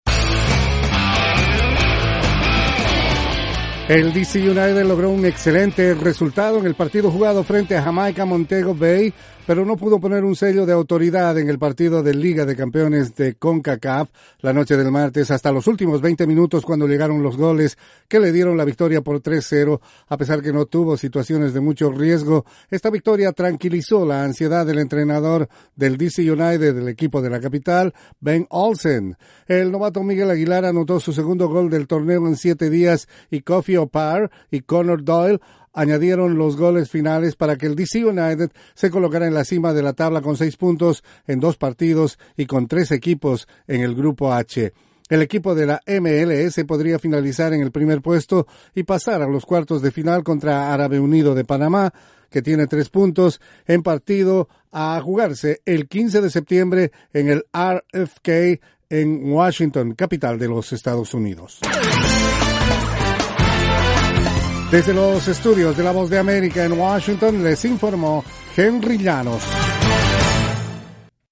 El equipo de fútbol de Washington, el DC United ganó en partido complicado al equipo Jamaicano de Montego Bay y podría terminar la serie primero si gana su próximo partido. Informa